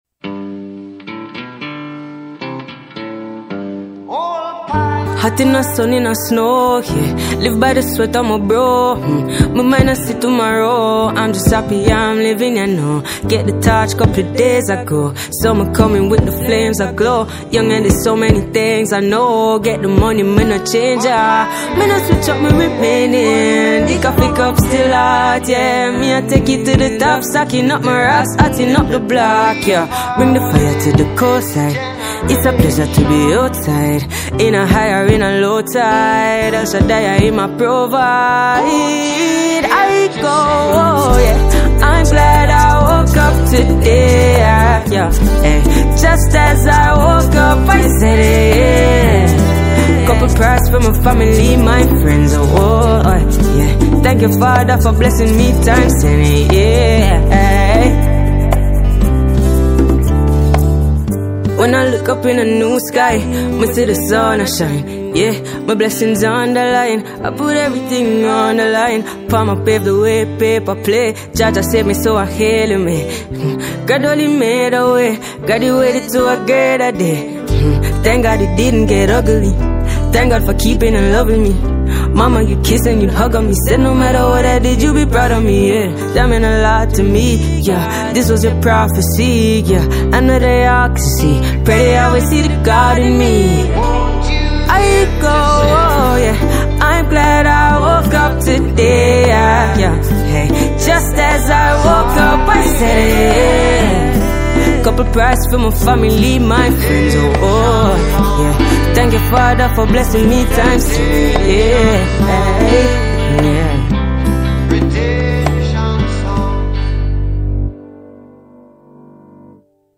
Jamaican reggae singer and songwriter
studio project